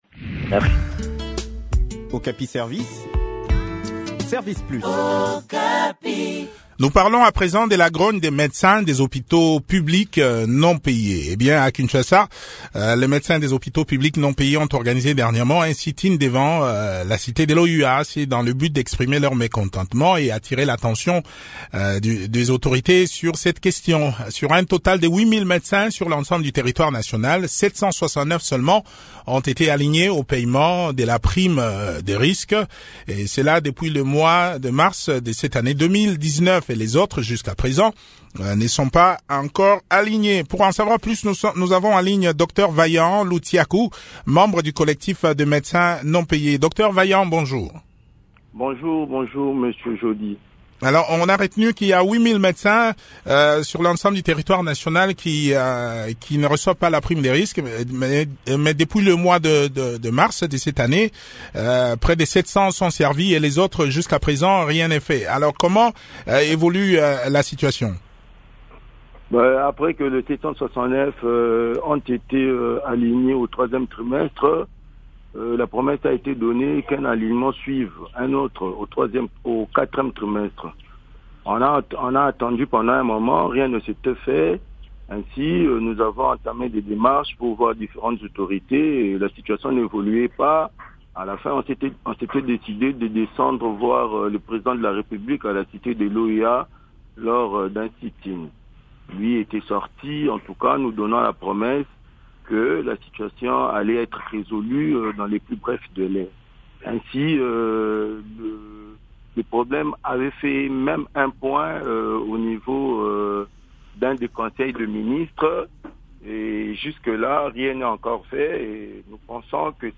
Le point de la situation dans cet entretien